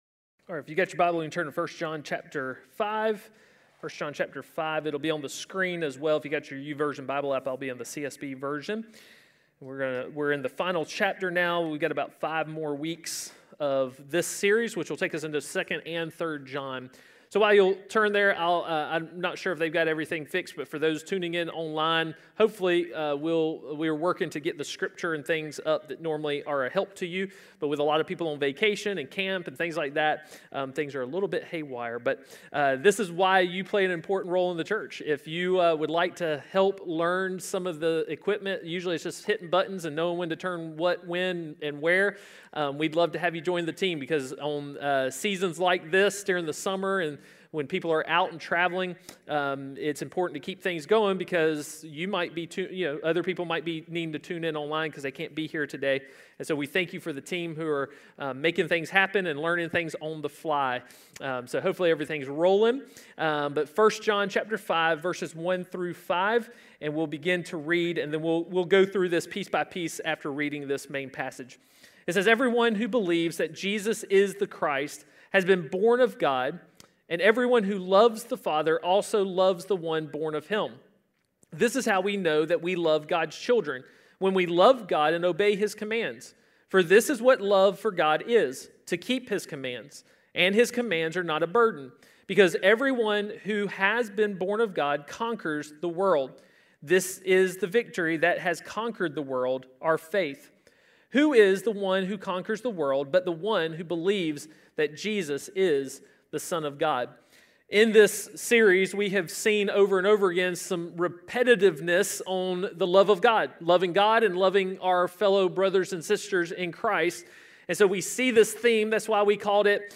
A message from the series "Unhindered."